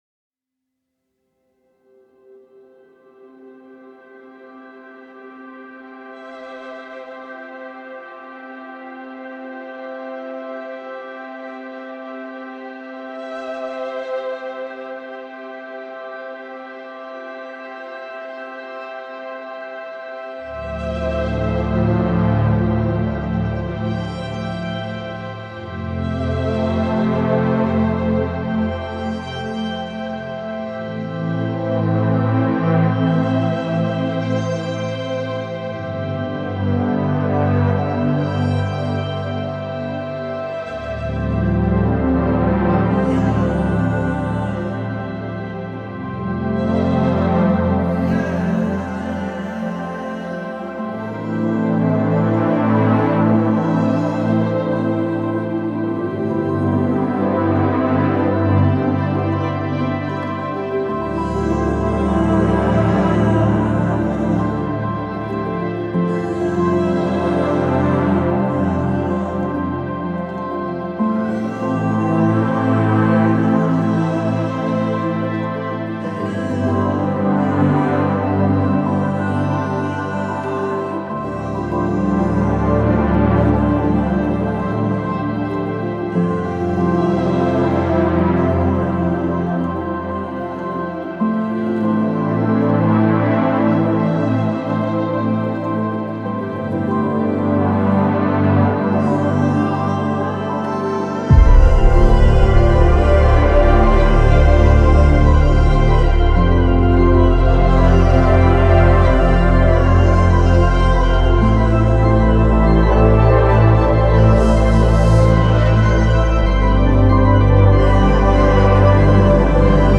• Жанр: Instrumental